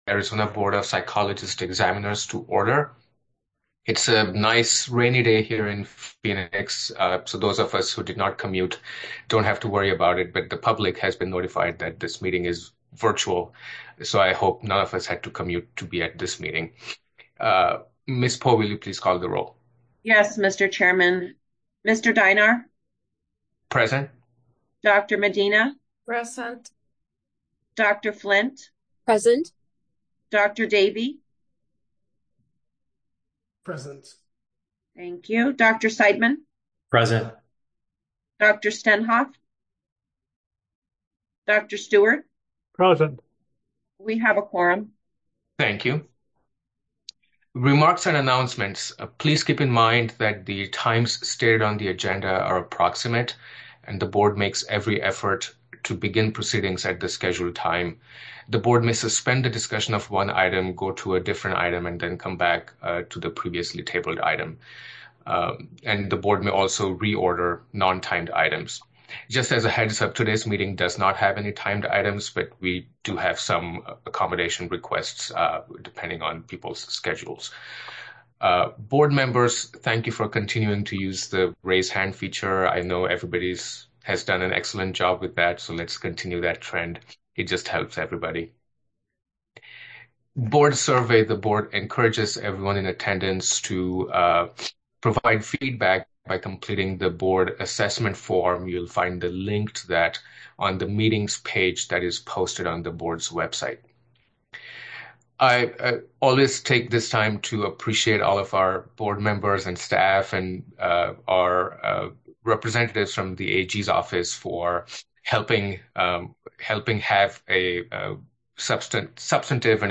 Board Meeting | Board of Psychologist Examiners
Members will participate via Zoom